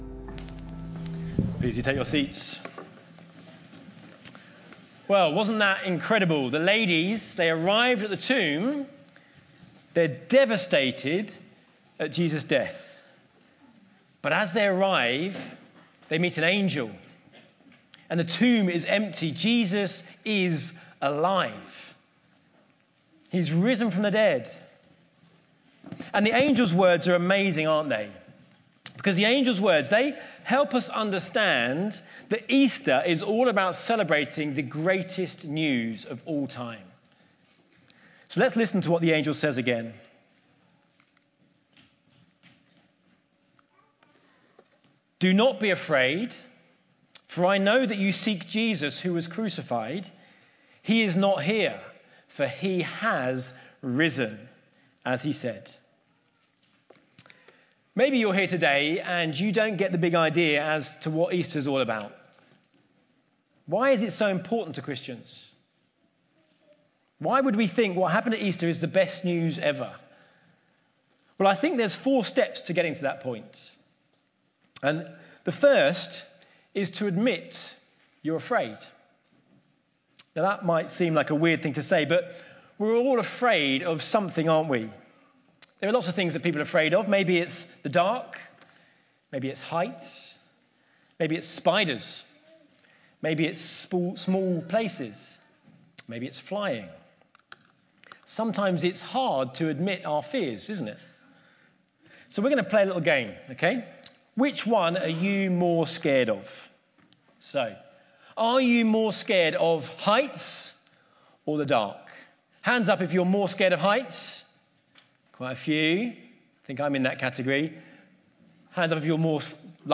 Sermon
easter-day-2025.mp3